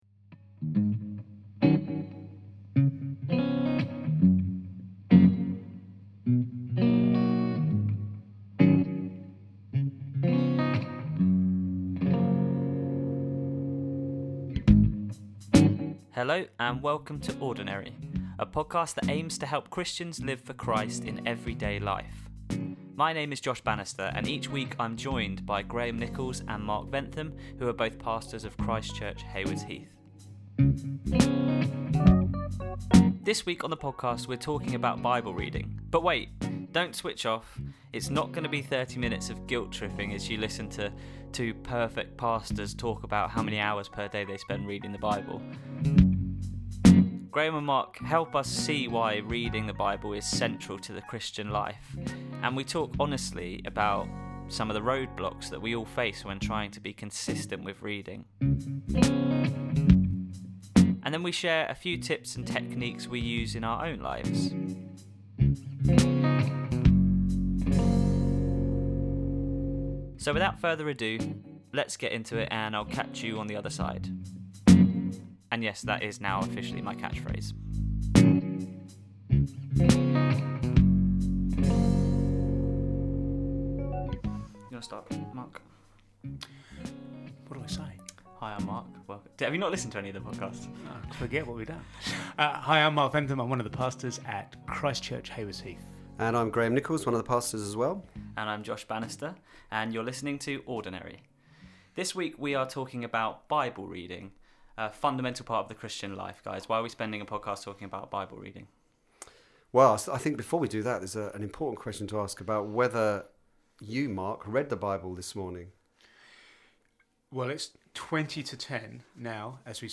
It’s not going to be 30 minutes of guilt tripping as you listen to two perfect pastors talk about how many hours per day they spend reading the Bible.